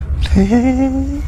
honk-honk-mp3cut.mp3